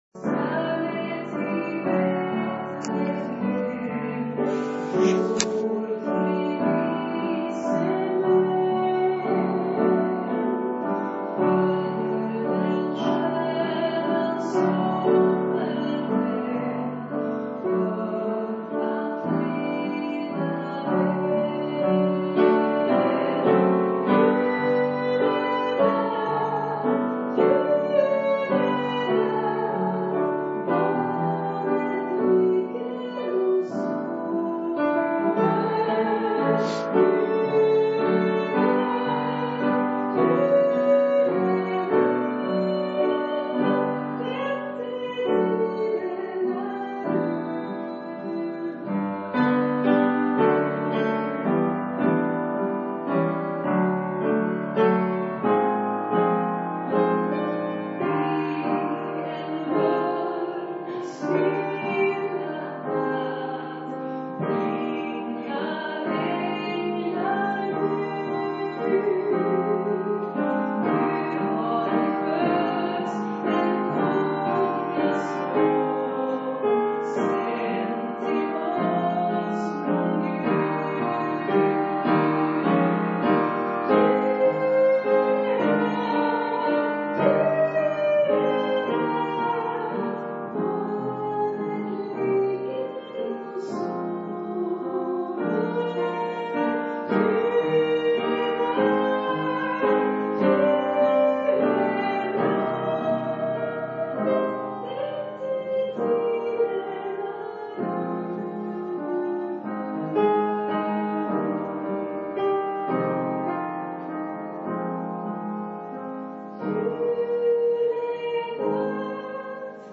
Predikan i Häggenkyrkan, Oxelösund.